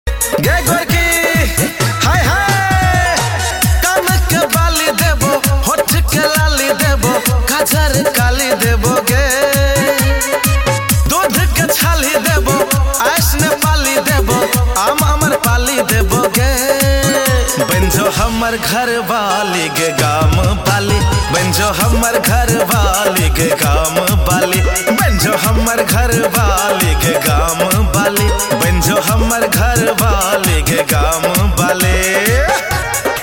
Maithili Song